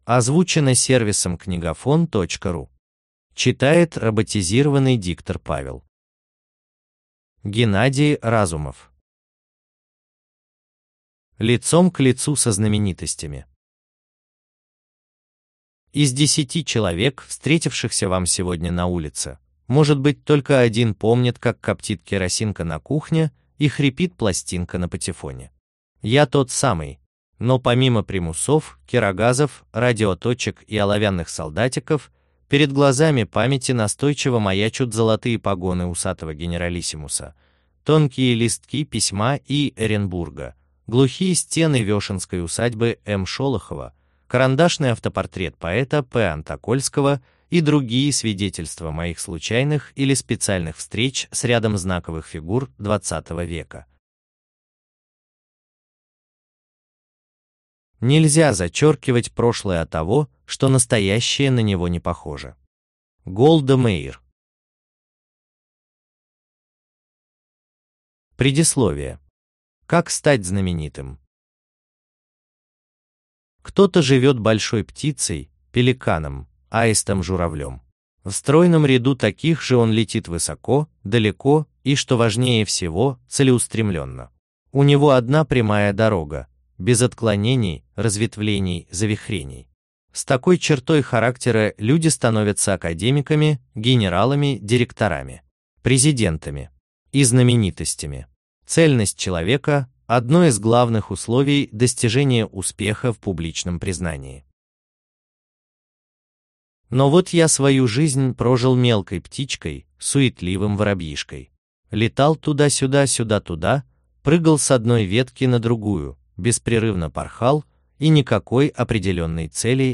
Аудиокнига Лицом к лицу со знаменитостями | Библиотека аудиокниг